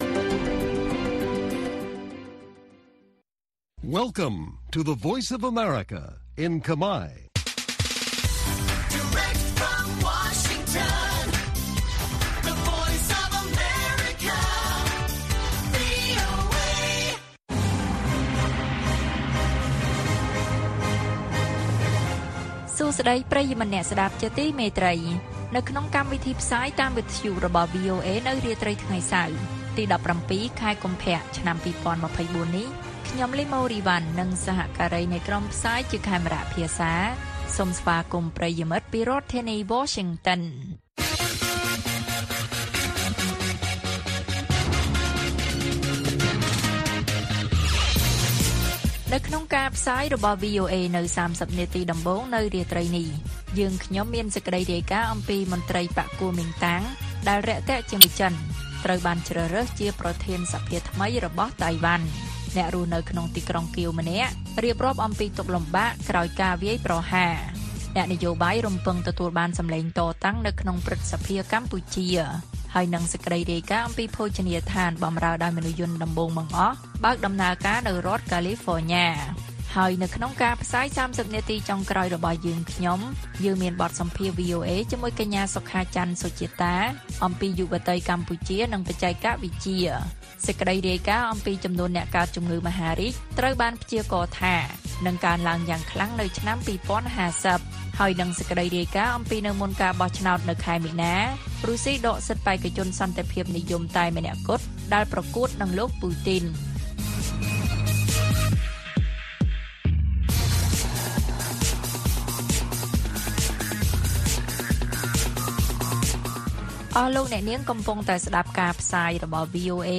ព័ត៌មាននៅថ្ងៃនេះមានដូចជា មន្ត្រីបក្សគួមីងតាងដែលរាក់ទាក់ជាមួយចិនត្រូវបានជ្រើសរើសជាប្រធានសភាថ្មីរបស់តៃវ៉ាន់។ អ្នករស់នៅក្នុងទីក្រុងកៀវម្នាក់រៀបរាប់អំពីទុក្ខលំបាកក្រោយការវាយប្រហារ។ បទសម្ភាសន៍